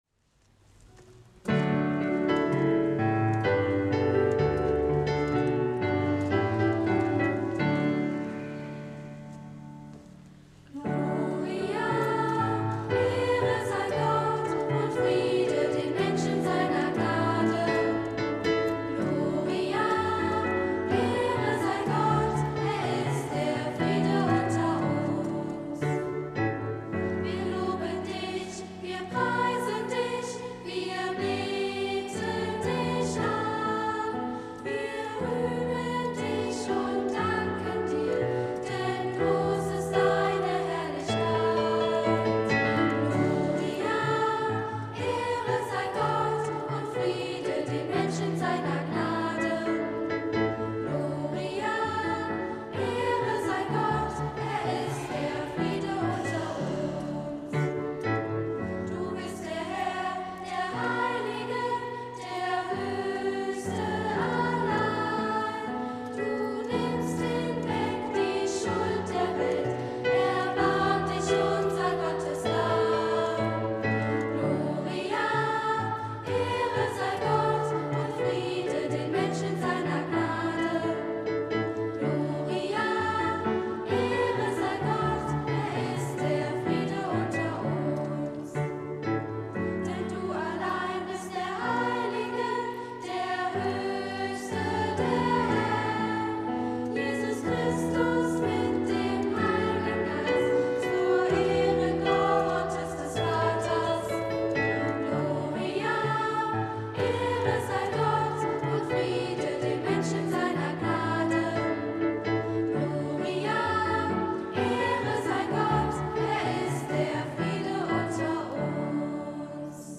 Musikalischer Abschluss der dritten Oktav 2013
Kinder- und Jugendchor
Projektchor